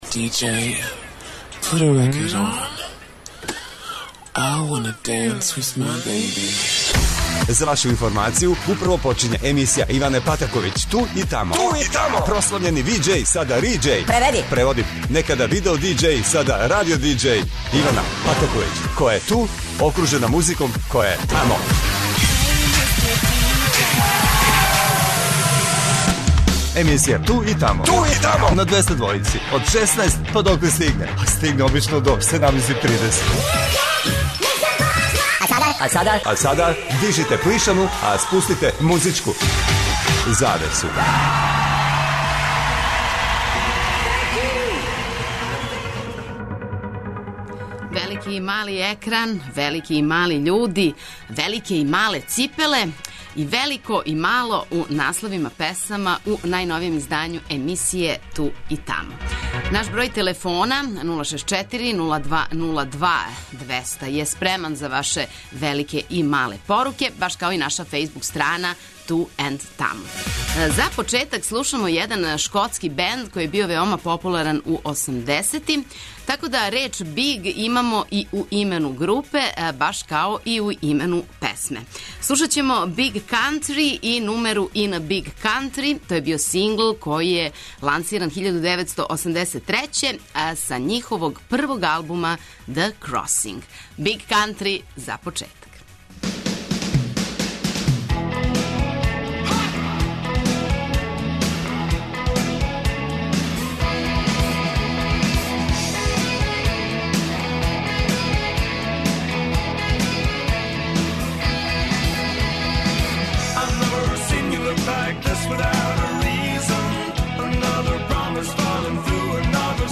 Значи велика доза добре музике на Двестадвојци.
Слушаоци могу у сваком тренутку да се укључе у "Ту и тамо" тему и дају своје предлоге...